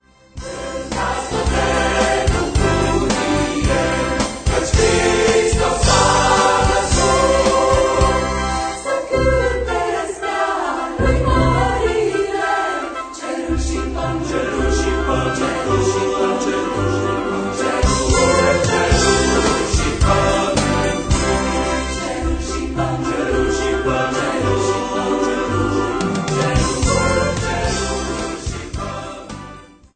colinde traditionale